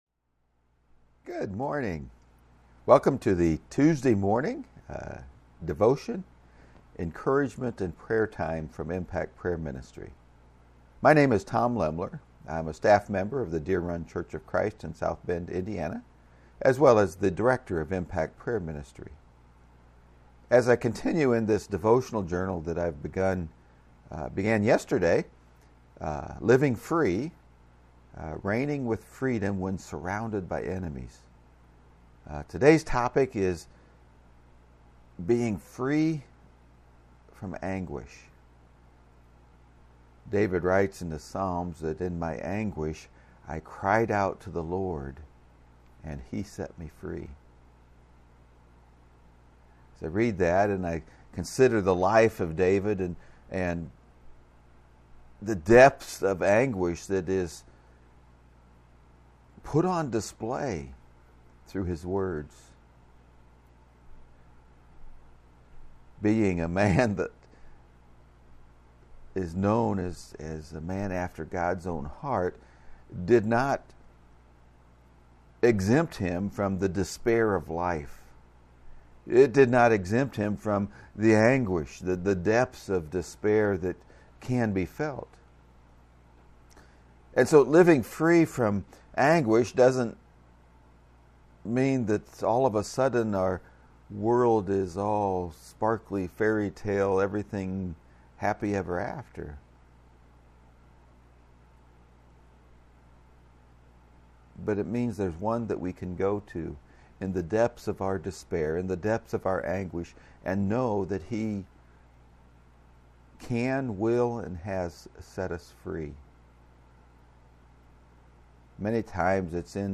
2020 sermon, “Let Freedom RING”